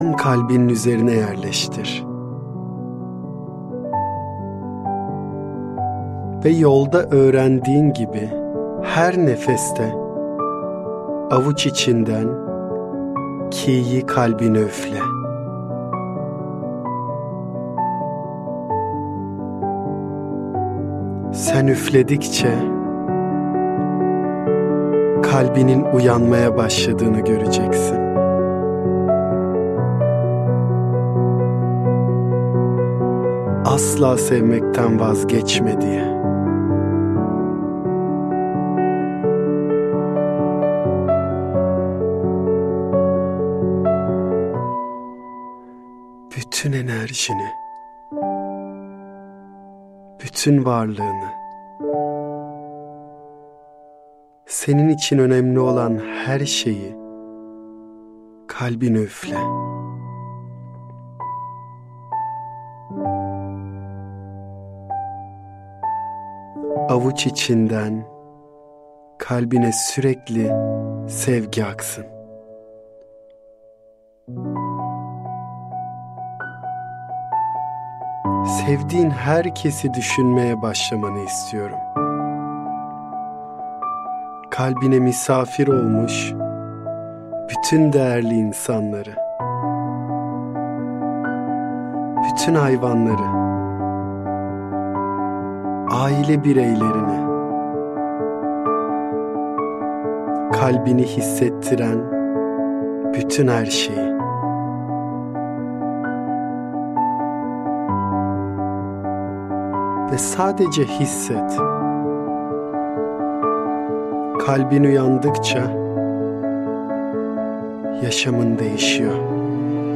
Meditasyon – Kalbin Uyanışı